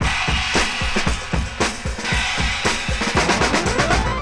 • 114 Bpm Fresh Breakbeat F Key.wav
Free drum loop - kick tuned to the F note. Loudest frequency: 1803Hz
114-bpm-fresh-breakbeat-f-key-HrU.wav